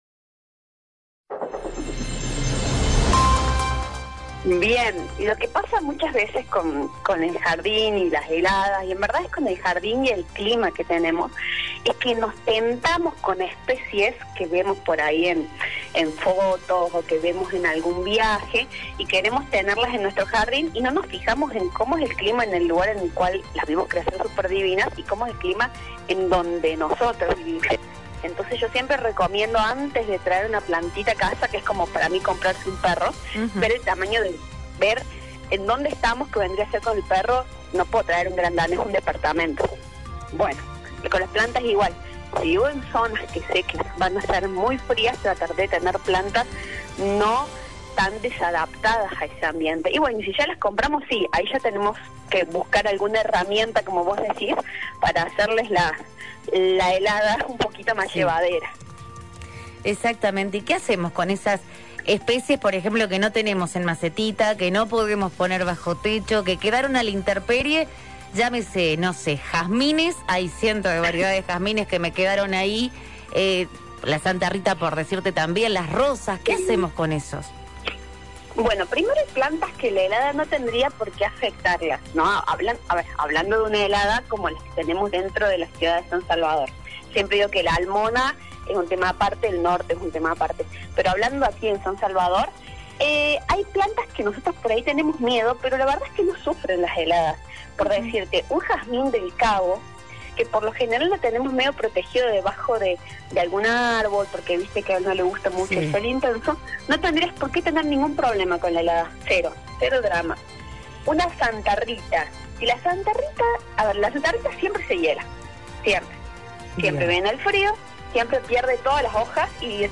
En dialogo con la ingeniera agrónoma